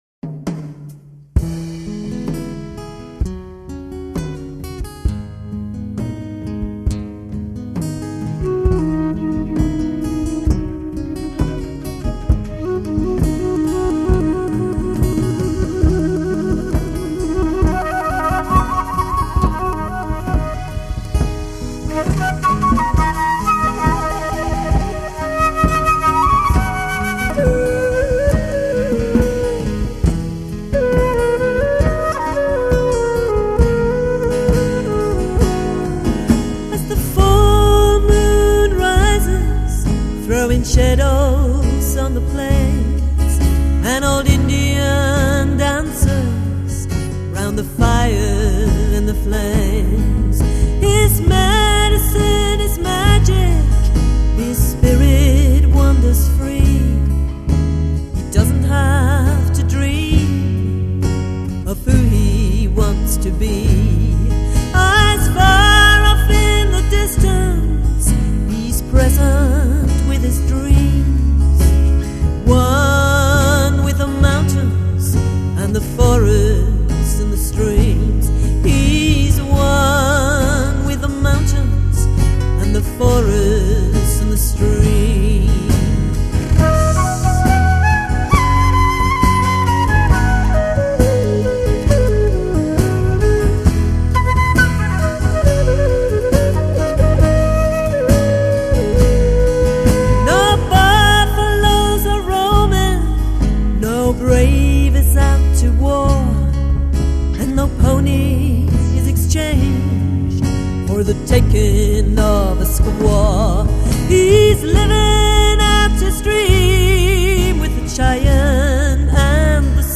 欧、美校园电台密集播放曲，居尔特民谣难得抒情佳作